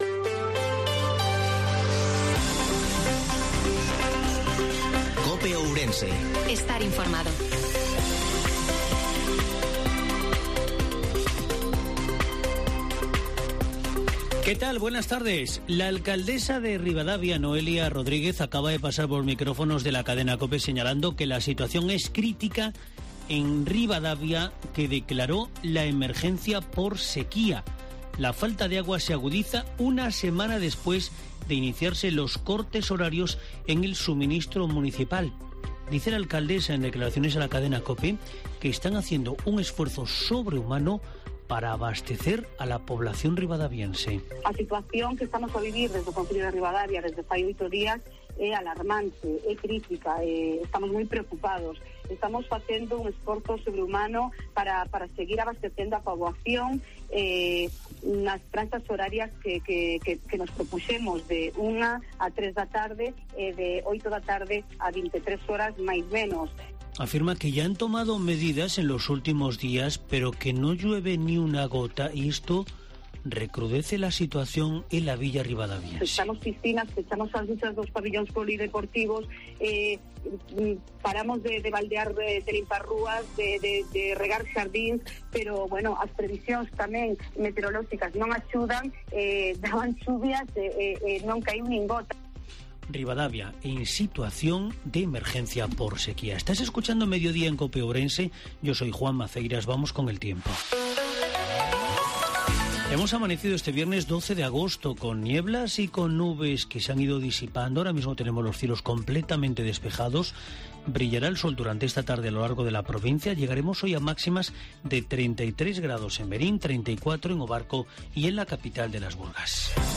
INFORMATIVO MEDIODIA COPE OURENSE-12/08/2022